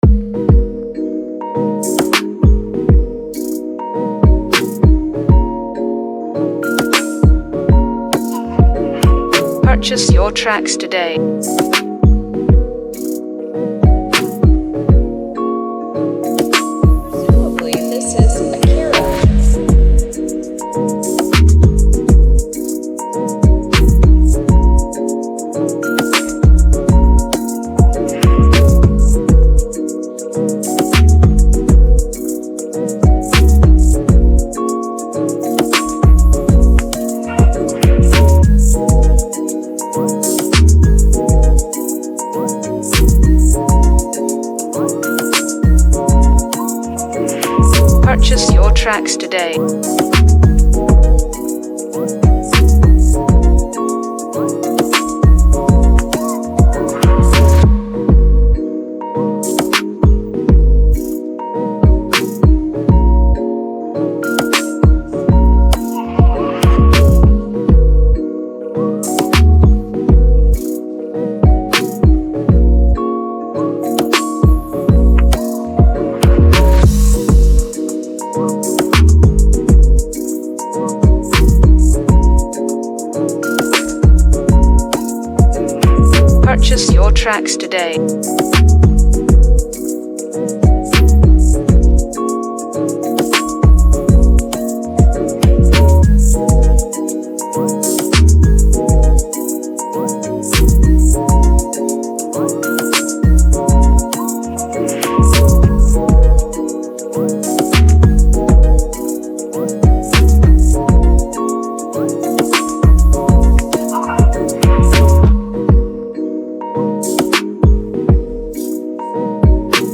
contemporary Afrobeat